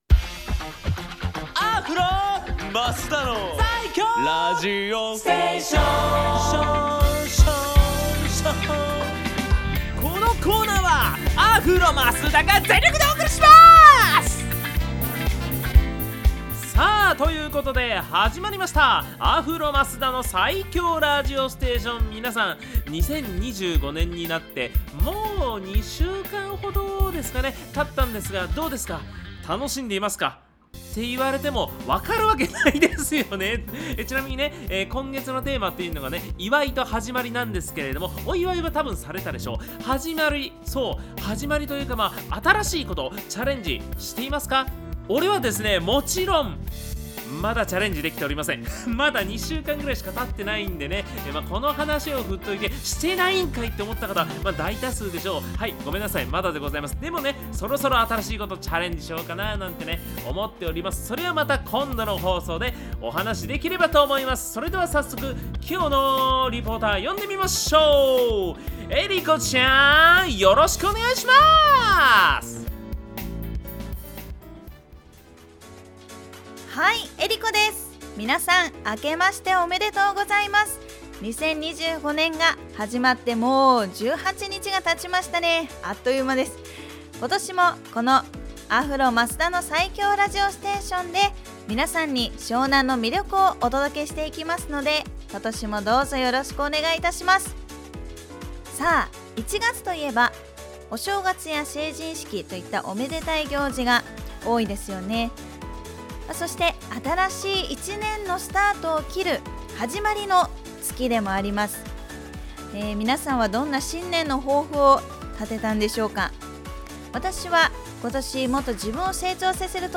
こちらのブログでは、FM83.1Mhzレディオ湘南にて放送されたラジオ番組「湘南MUSICTOWN Z」内の湘南ミュージックシーンを活性化させる新コーナー！